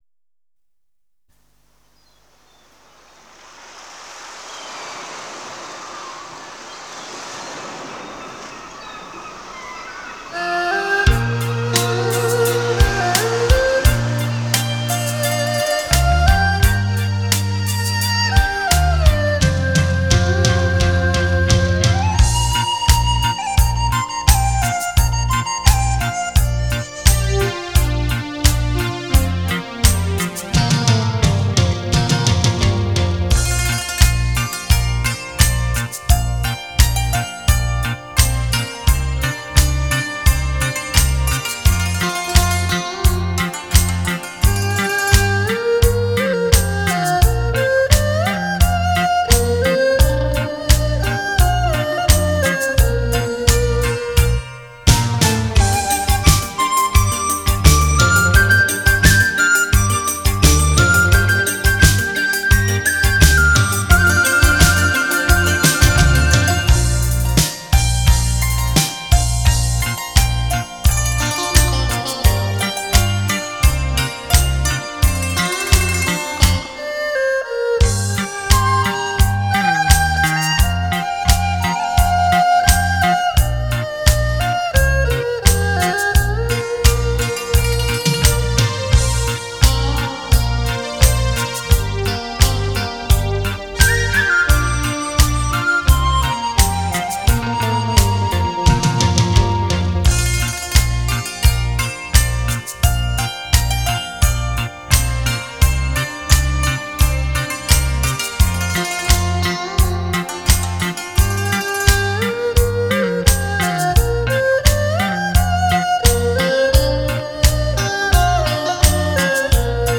狮城华乐好手